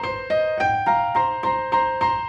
Index of /musicradar/gangster-sting-samples/105bpm Loops
GS_Piano_105-C2.wav